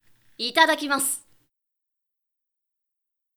ダウンロード 中性_「いただきます」
中性挨拶